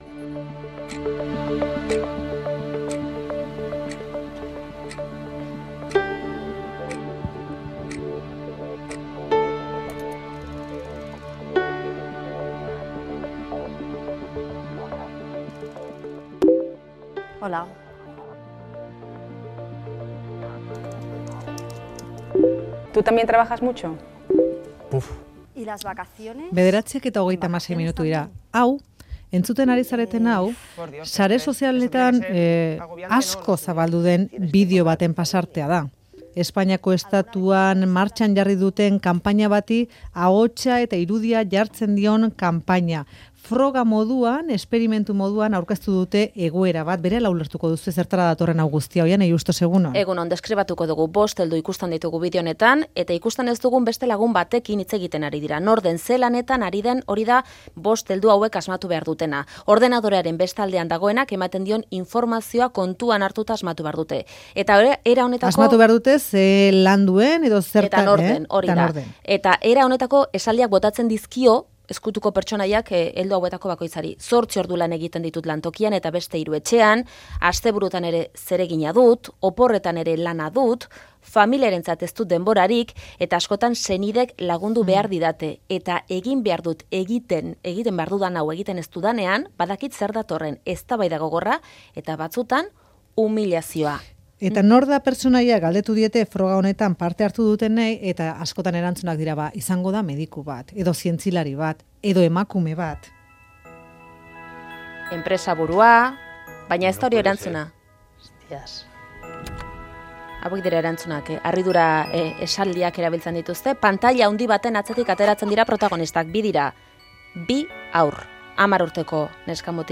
Haurren etxerako lanen inguruko eztabaida, Faktoria Euskadi Irratian.